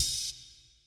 Crashes & Cymbals
CC - Oh Crash.wav